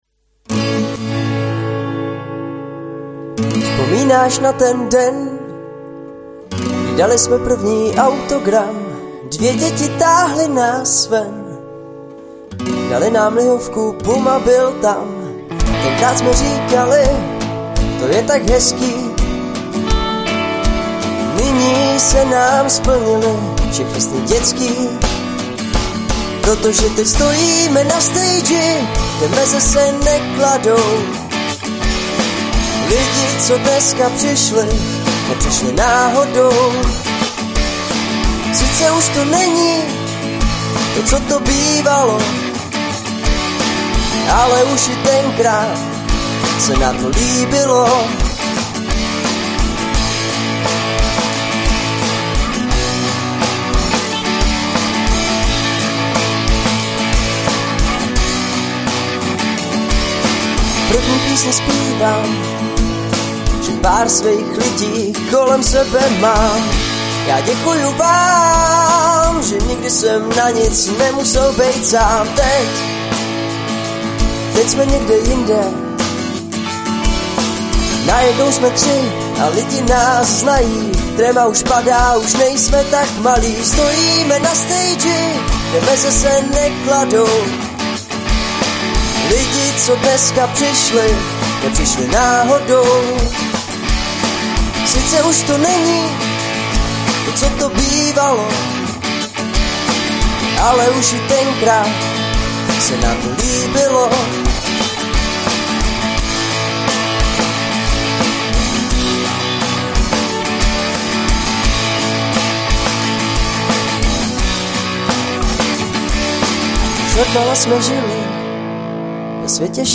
Hrajeme převážně pop-folk.